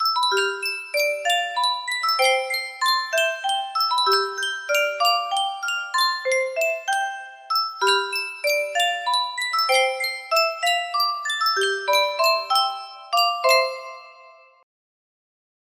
Sankyo Spieluhr - Blumenlied AOC music box melody
Full range 60